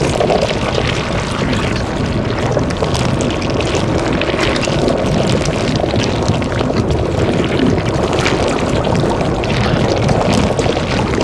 Sfx_creature_shadowleviathan_seatruckattack_loop_layer_metal_01.ogg